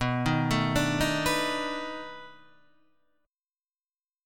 B7#9 chord {7 6 7 7 4 7} chord